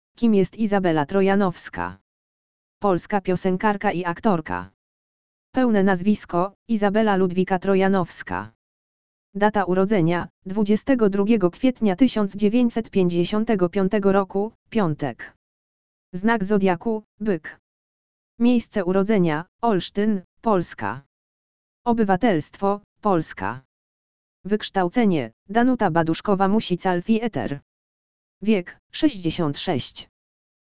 lektor_audio_urodziny_izabeli_trojanowskiej.mp3